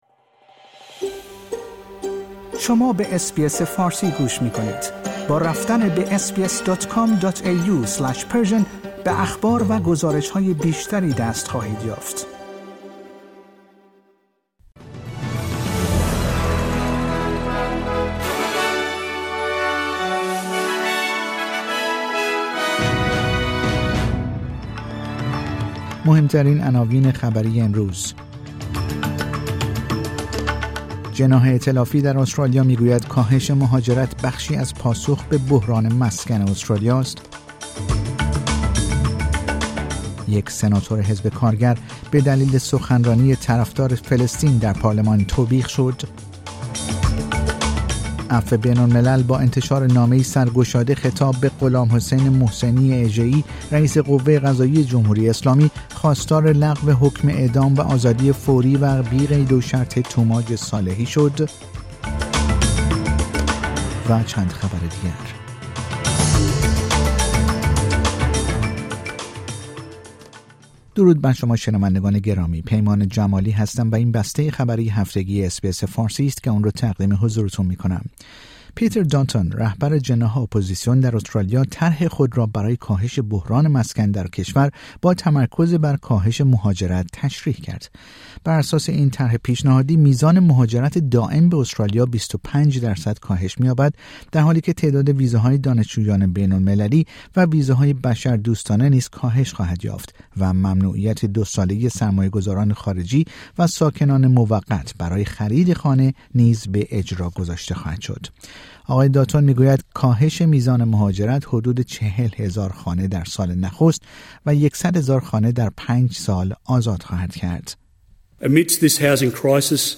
در این پادکست خبری مهمترین اخبار استرالیا، جهان و ایران در یک هفته منتهی به شنبه ۱۸مه ۲۰۲۴ ارائه شده است.